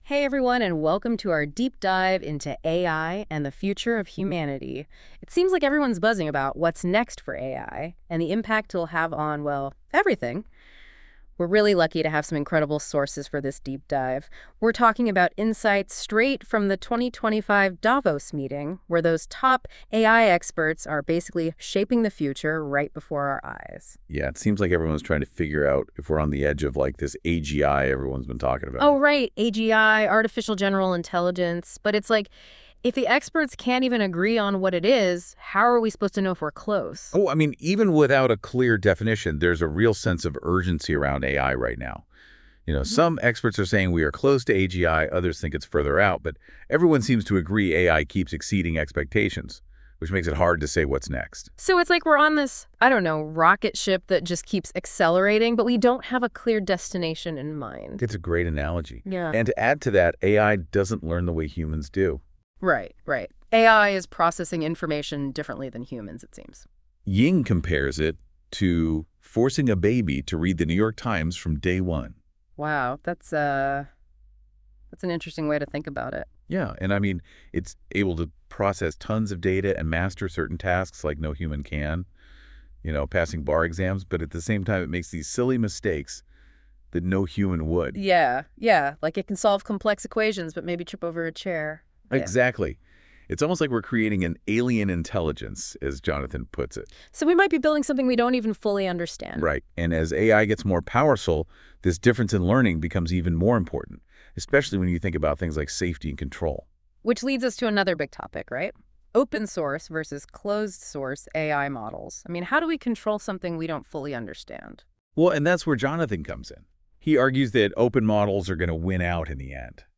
Als besonders innovativ wurde von den Rezensent:innen die Möglichkeit zur Erstellung eines Podcasts („Audio-Overview“) hervorgehoben, in dem zwei KI-Hosts eine natürliche und lebendige Diskussion zu jedem erdenklichen Thema führen, das in den hochgeladenen Dokumenten behandelt wird.